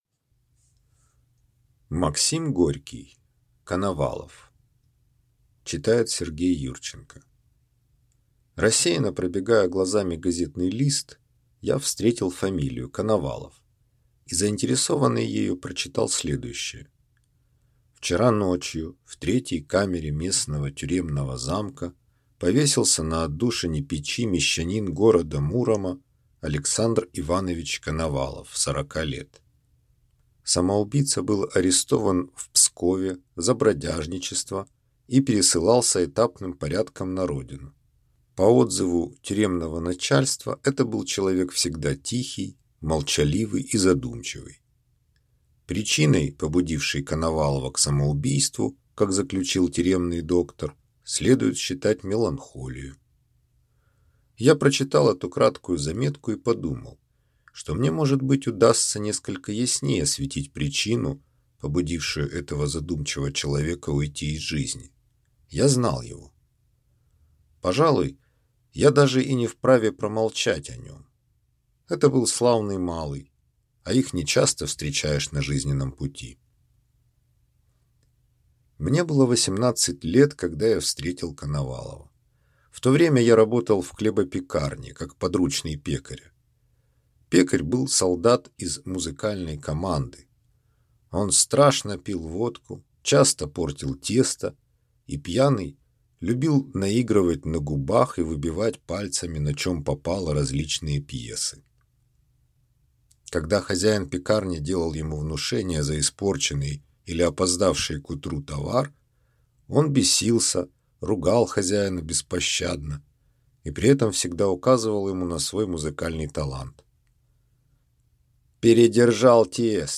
Аудиокнига Коновалов - купить, скачать и слушать онлайн | КнигоПоиск